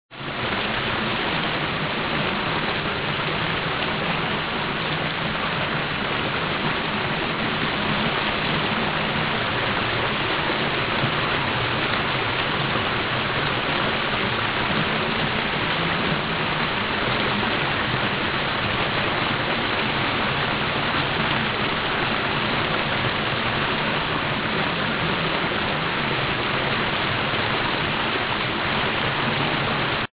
vesisolina.mp3